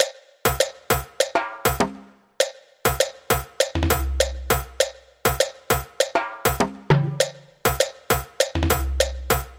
Marimba CDmFG
描述：Chord progression: CDmFG. Created with Logic Pro X. Maybe used for Tropical House
标签： 100 bpm House Loops Percussion Loops 1.62 MB wav Key : Unknown
声道立体声